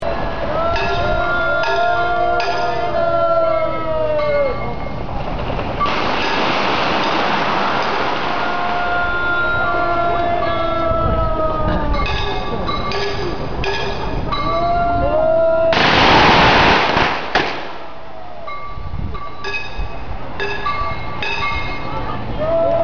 長崎のお盆に響き渡る爆竹音には、いろんな思いがこもっている。